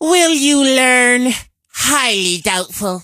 carl_kill_vo_03.ogg